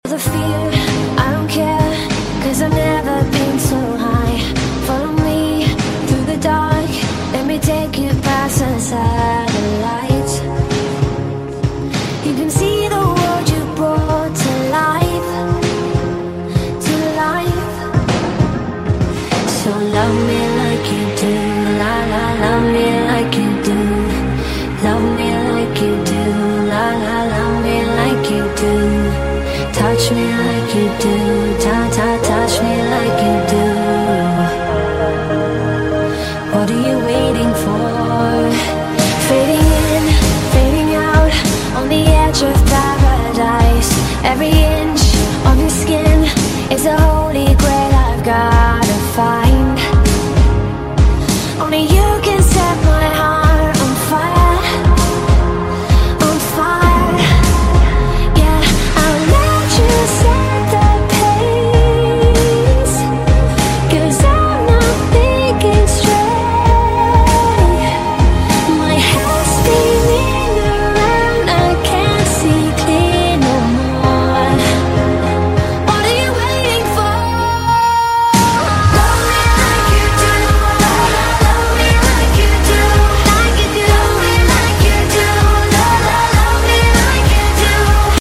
ASMR PINK EMOJI FOOD kohakuto sound effects free download
ASMR PINK EMOJI FOOD kohakuto ice sprinkle honey jelly mukbang eating sounds